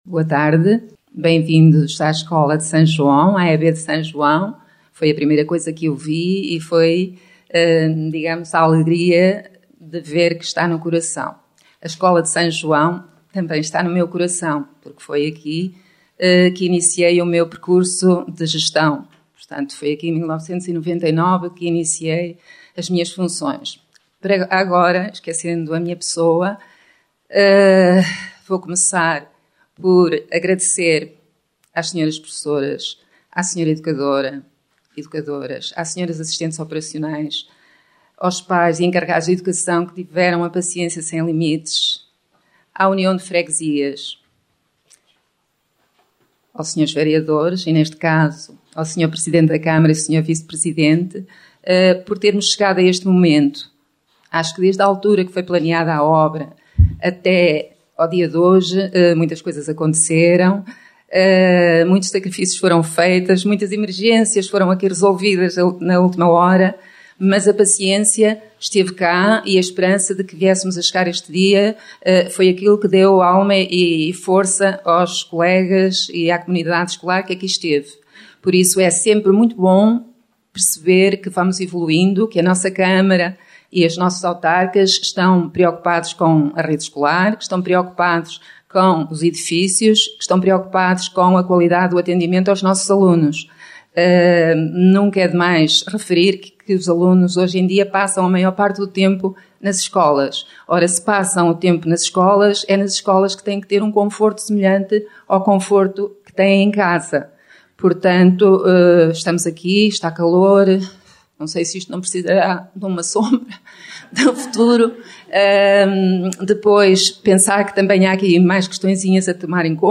Escola Básica de S. João inaugurada no Dia do Município após obra de ampliação e requalificação
No passado dia 25 de Julho, celebrou-se o Dia do Município, com a Câmara Municipal de Ovar a proceder a várias cerimónias e atos públicos que assinalam melhorias no território.